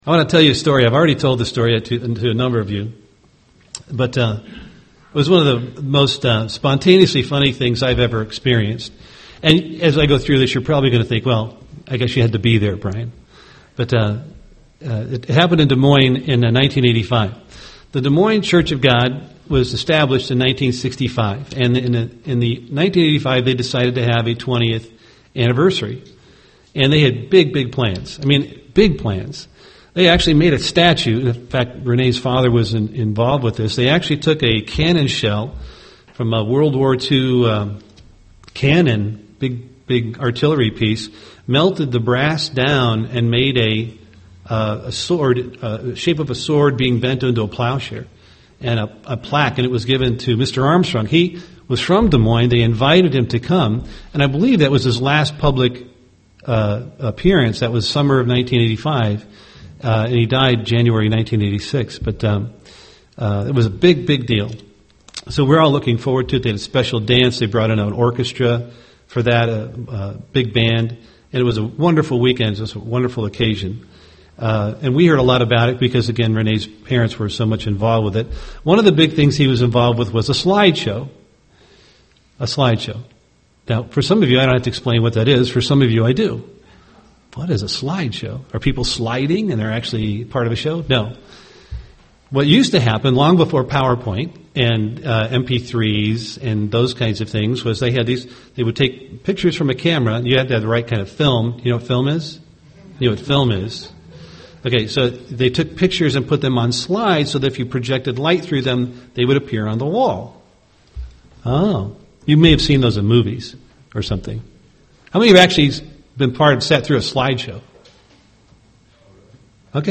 UCG Sermon character development Studying the bible?